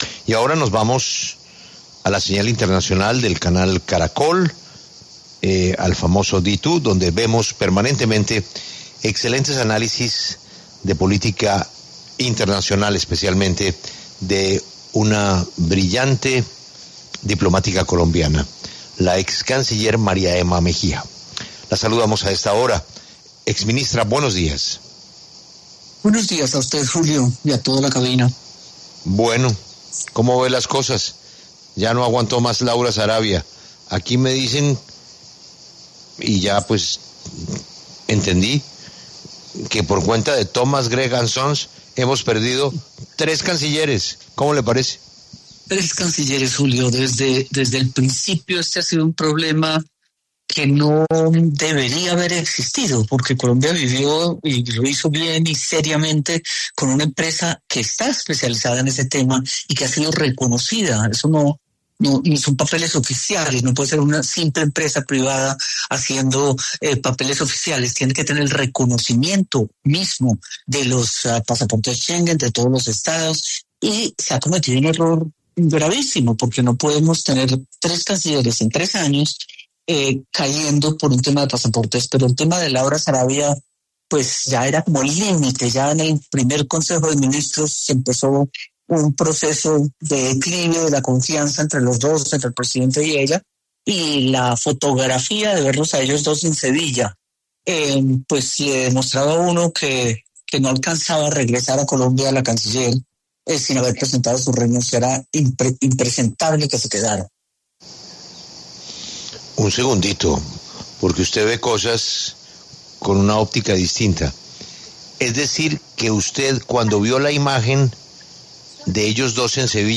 La excanciller María Emma Mejía pasó por los micrófonos de La W y se refirió al reciente anuncio de la renuncia de Laura Sarabia como canciller.